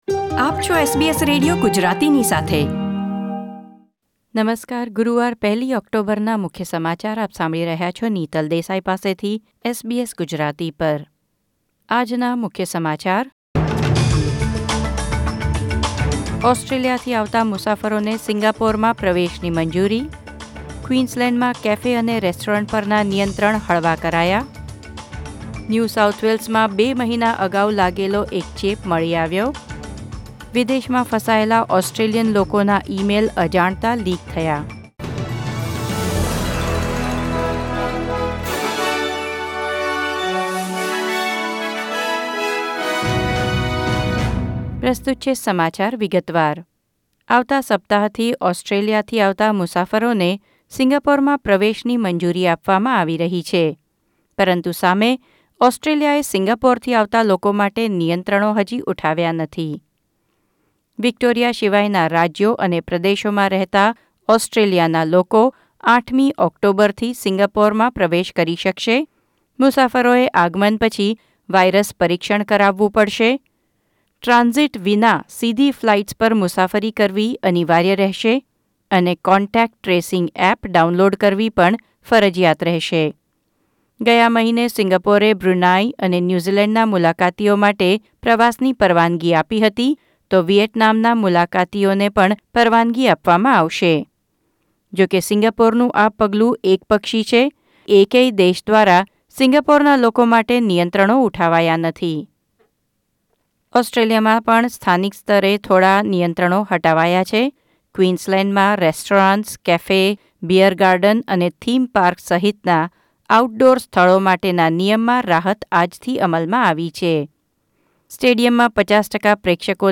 SBS Gujarati News Bulletin 1 October 2020